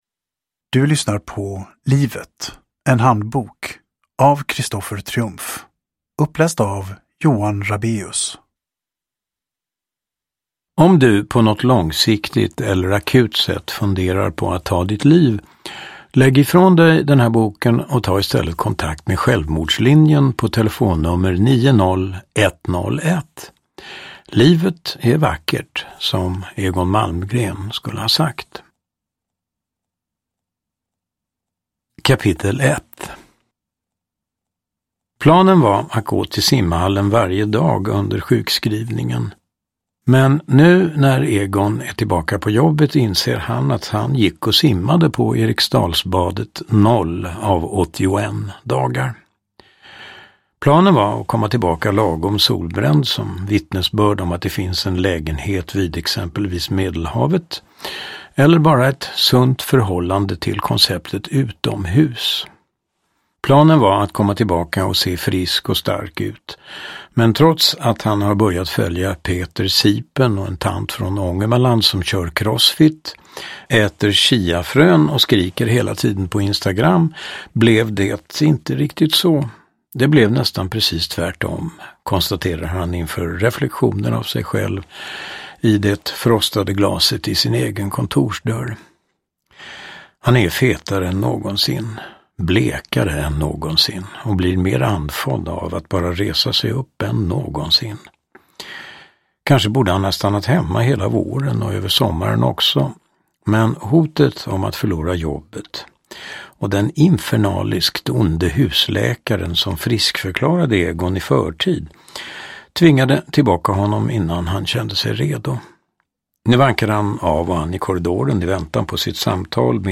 Livet - en handbok : roman – Ljudbok
Uppläsare: Johan Rabaeus